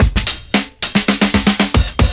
112FILLS10.wav